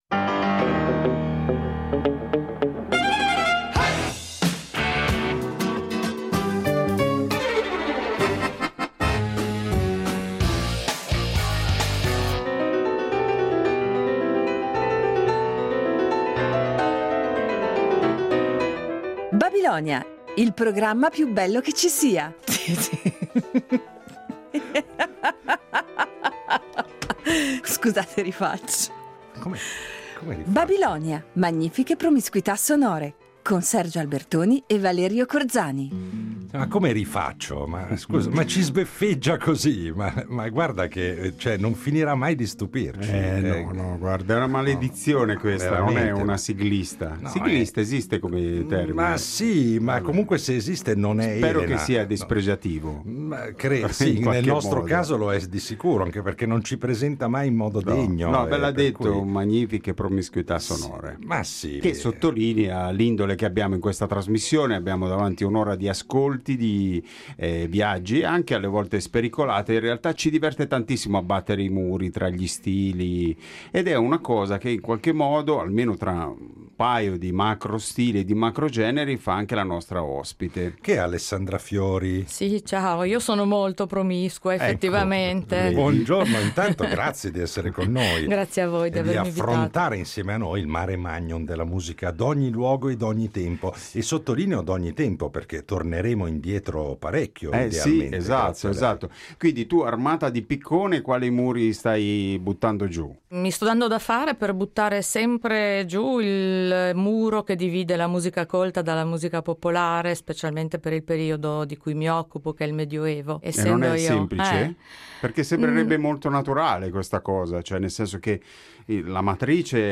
Musica antica e non solo, anzi di tutto e di più come da sana abitudine babilonica, con un’ospite graditissima che anche oggi va ad arricchire i nostri sbalestranti itinerari sonori.